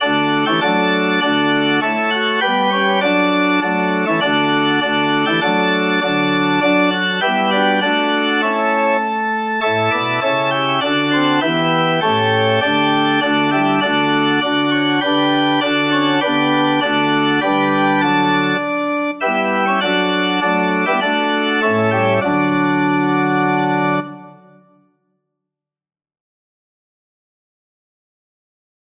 Christmas Christmas Organ Sheet Music While Shepherds Watched Their Flocks by Night
4/4 (View more 4/4 Music)
D major (Sounding Pitch) (View more D major Music for Organ )
Organ  (View more Intermediate Organ Music)
Traditional (View more Traditional Organ Music)